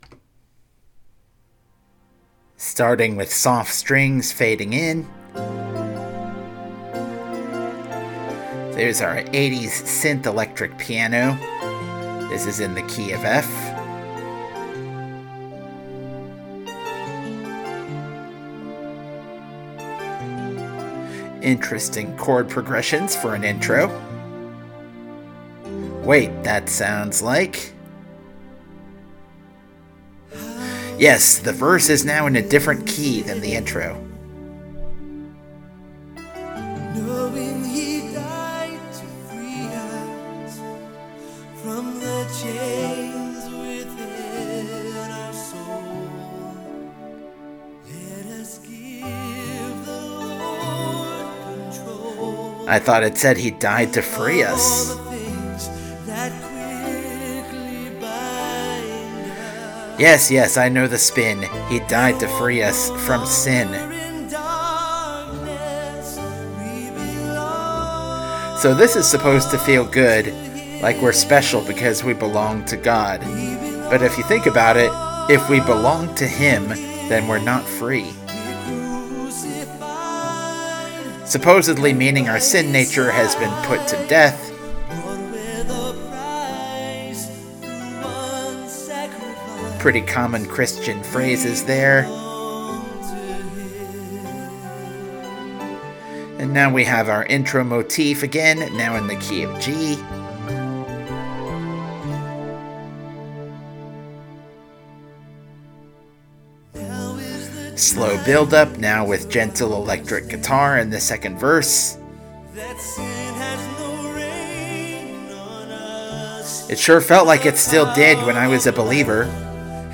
Trying something new for me: geeking out over a piece of music from my teenage years, with an audio commentary.
we-belong-to-him-commentary.mp3